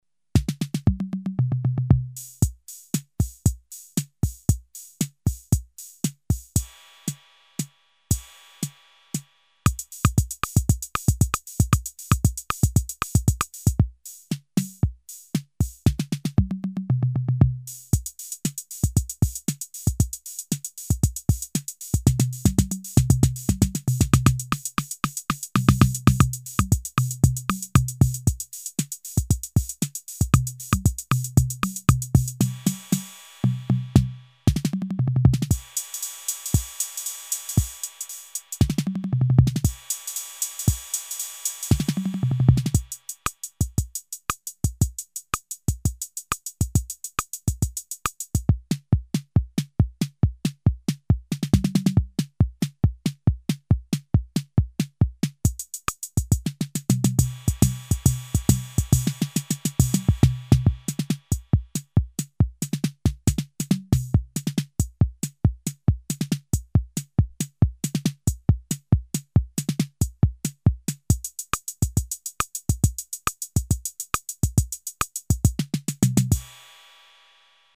Class: Drum Machine
rhythm pattern
demo rhythm pattern with accent